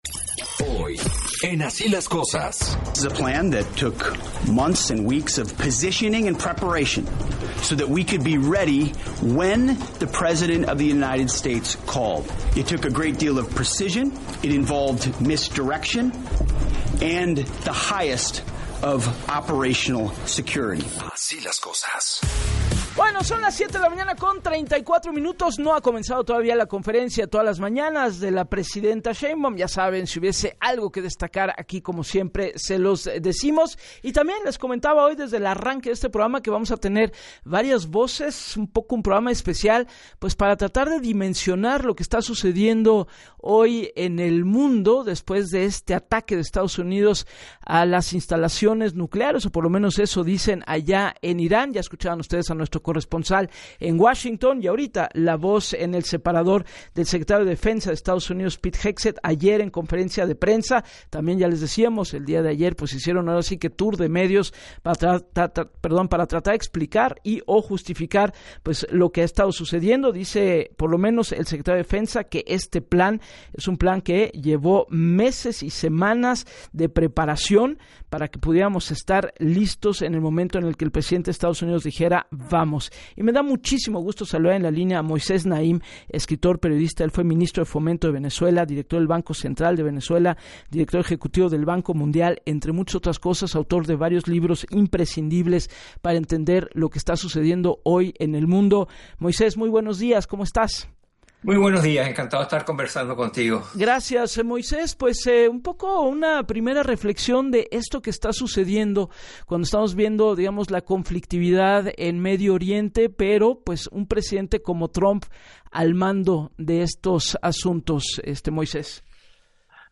En entrevista con Gabriela Warkentin, el escritor y periodista, Moisés Naím, informó que el ataque a instalaciones nucleares de Irán fue perfecto desde el punto de vista militar, otros países tratarán de emular la tecnología que utilizaron, la pregunta que tiene todo el mundo es ¿qué va a pasar ahora?, no está claro cuál es el próximo paso.